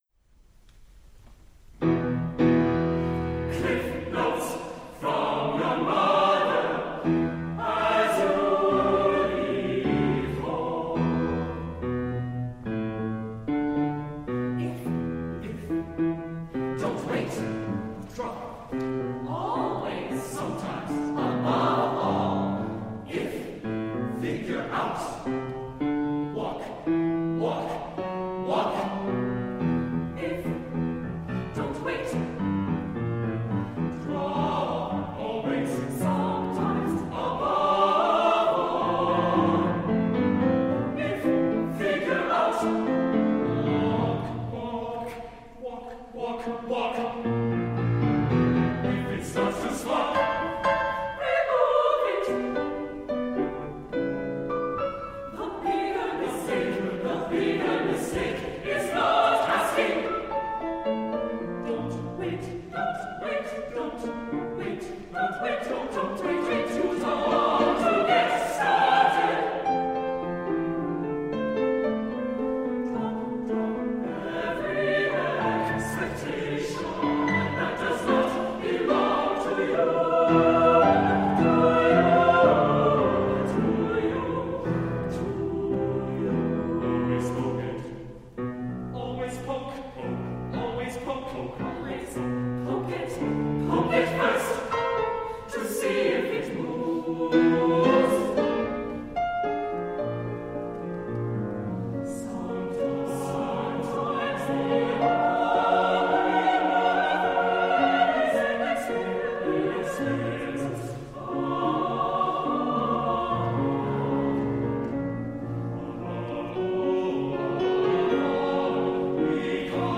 SATB, piano